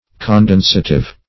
Search Result for " condensative" : The Collaborative International Dictionary of English v.0.48: Condensative \Con*den"sa*tive\, a. [Cf. F. condensatif.]
condensative.mp3